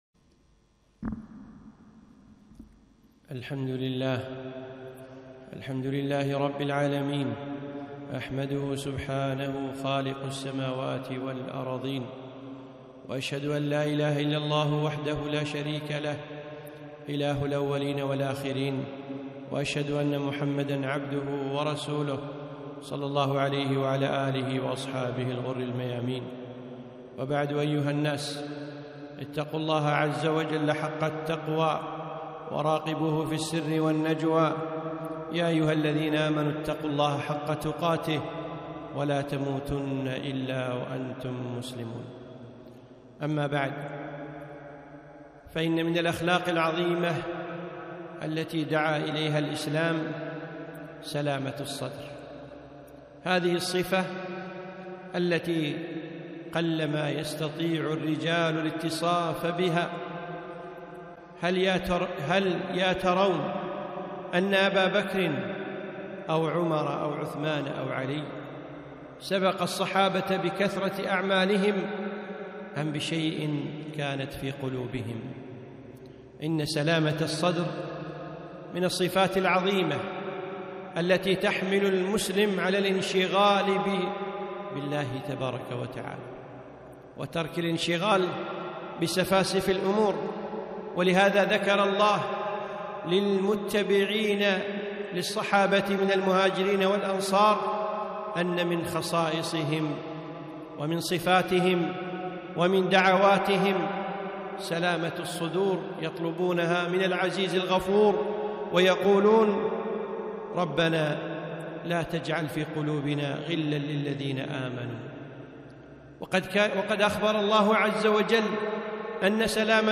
خطبة - سلامة الصدر